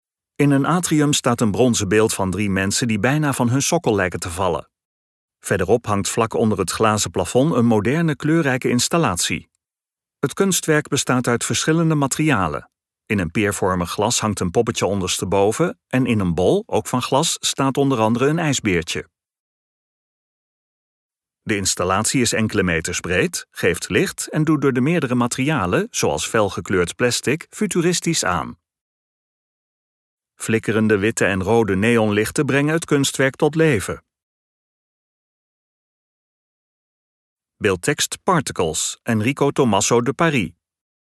OPGEWEKTE MUZIEK TOT HET EIND VAN DE VIDEO